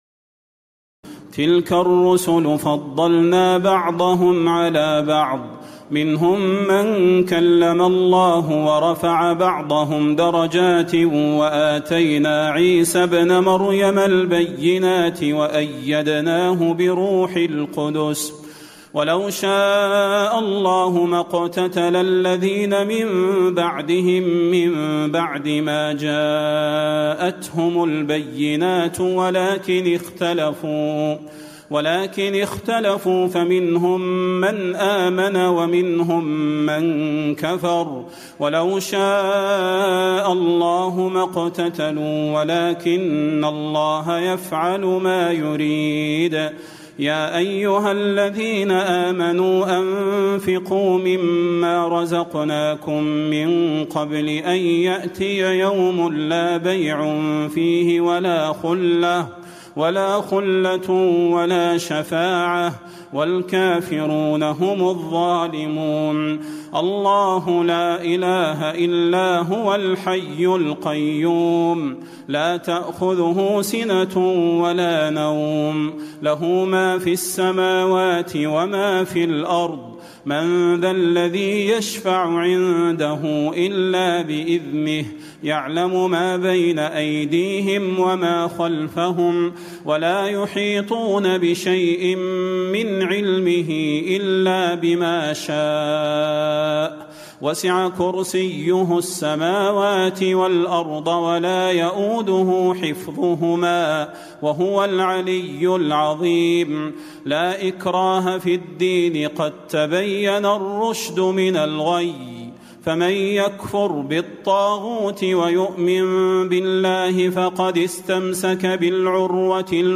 تراويح الليلة الثالثة رمضان 1436هـ من سورتي البقرة (253-286) و آل عمران (1-13) Taraweeh 3st night Ramadan 1436H from Surah Al-Baqara and Surah Aal-i-Imraan > تراويح الحرم النبوي عام 1436 🕌 > التراويح - تلاوات الحرمين